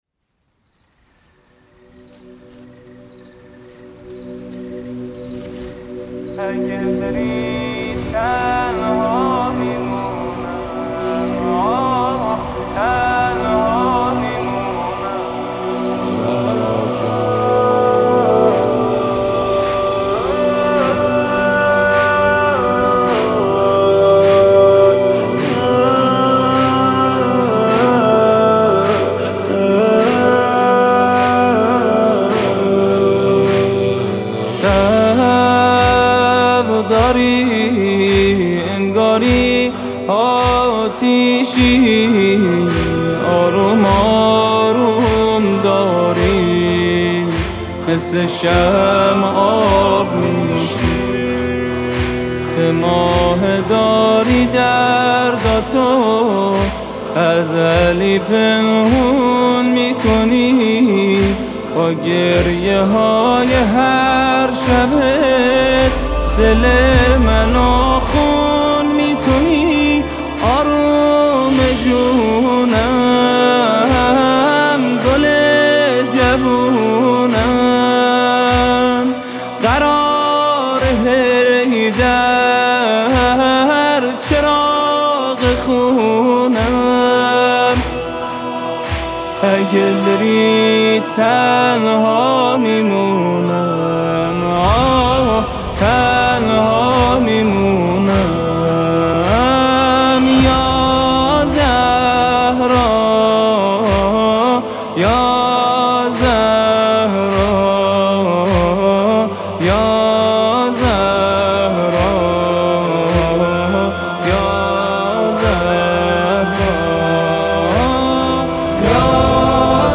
اثری آوایی